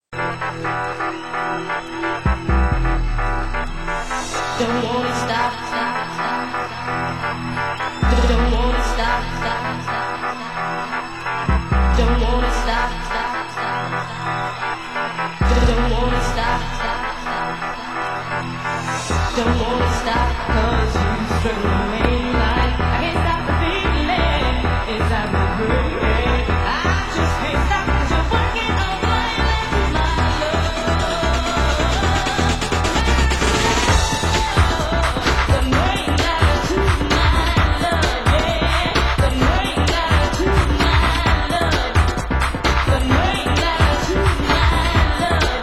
Genre: Break Beat
Genre: UK Techno